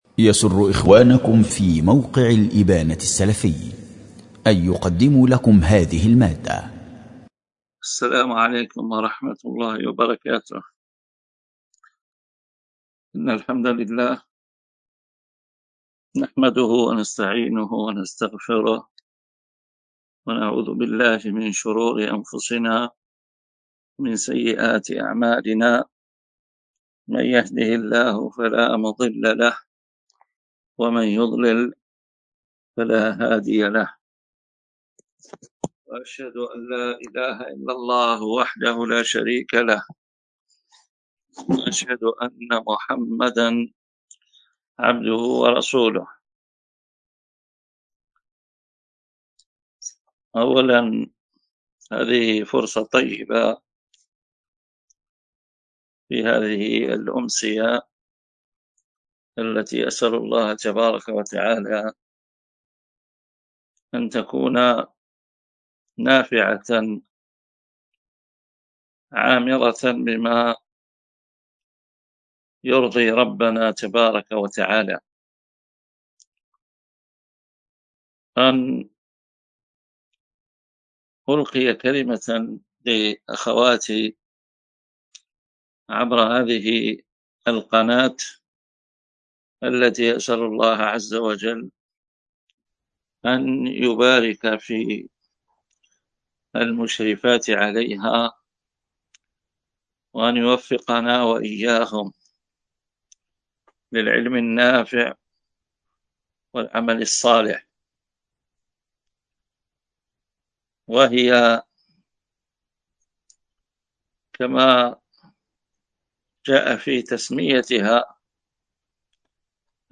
دروس ومحاضرات